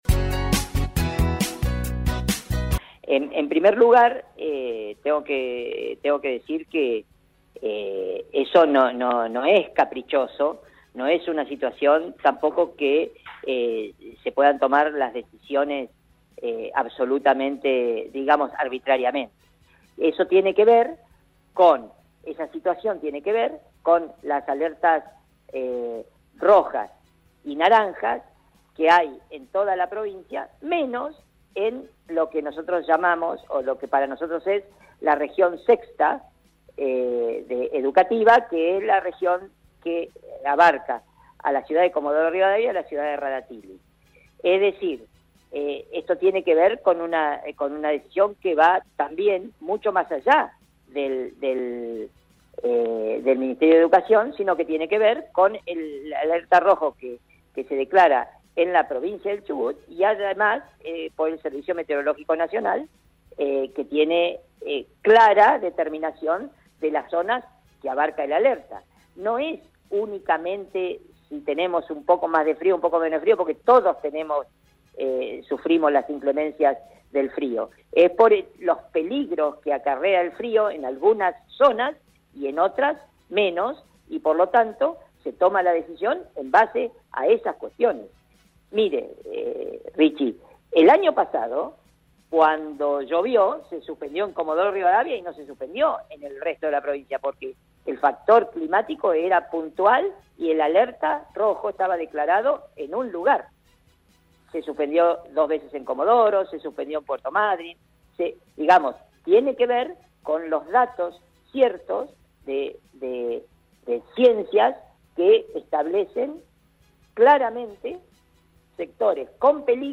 El Ministro de educación, José Luis Punta, dialogó con LA MAÑANA DE HOY y esto dijo al respecto: